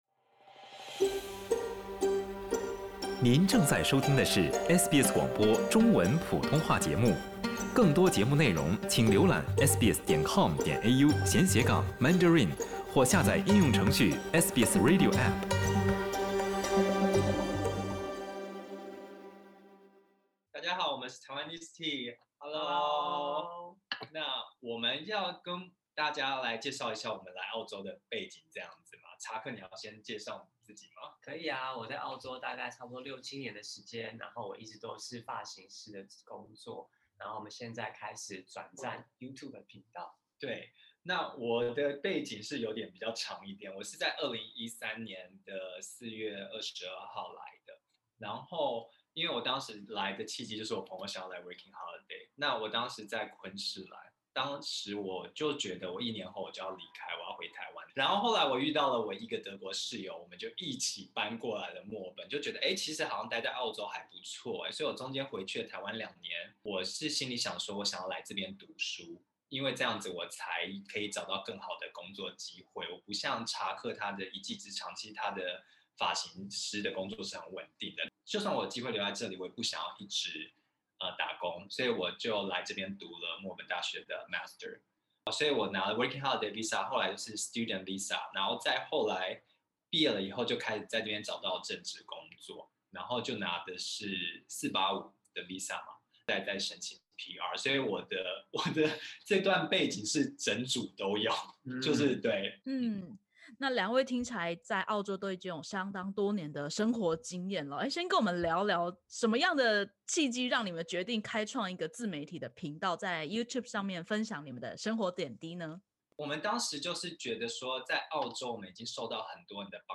（点击首图收听采访音频）